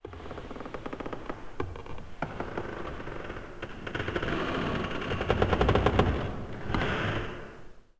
woodcreak2b.wav